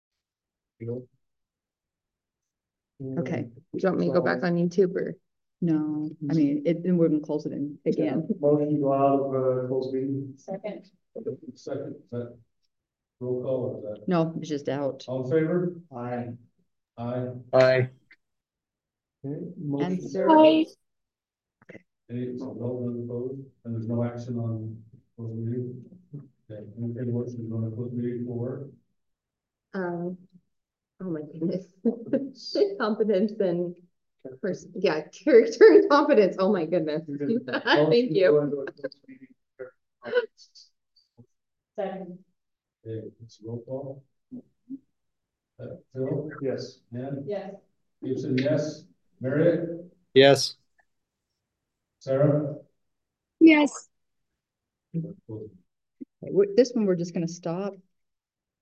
Public Notice is hereby given that the City Council of Pleasant View, Utah will hold a Public Meeting in the city office at 520 West Elberta Dr. in Pleasant View, Utah on Tuesday, February 13, 2024, commencing at 6:00 PM.